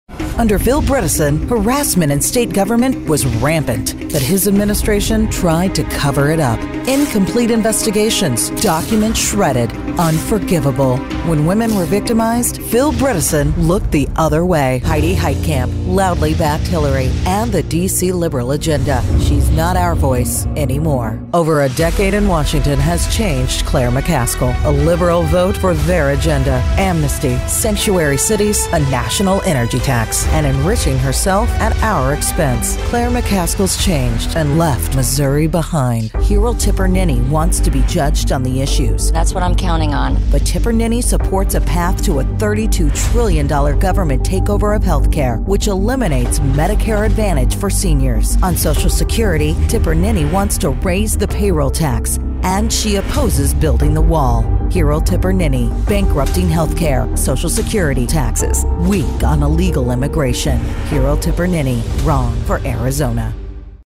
Female
trustworthy, confident, warm, wry, sarcastic, informational, authoritative, fun, news, promo, voice of god, real, smart, engaging, conversational, source-connect
Microphone: Sennheiser MKH 416